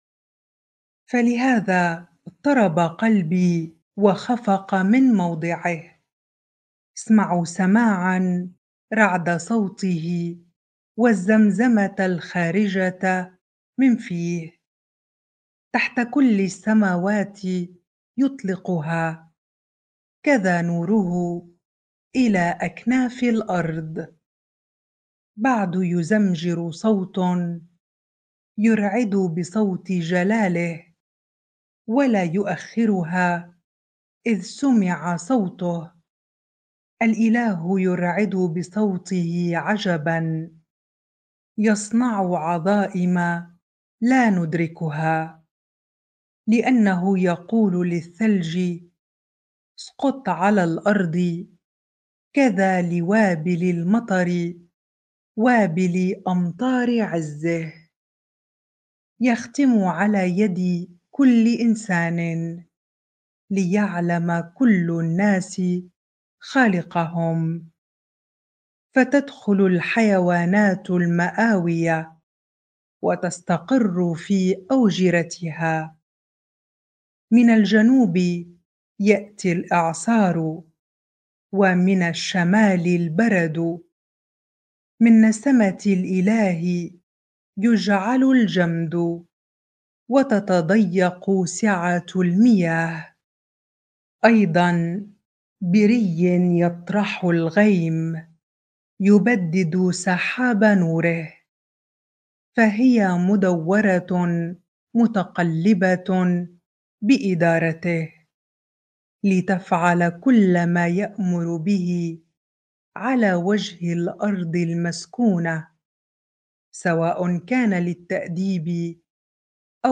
bible-reading-Job 37 ar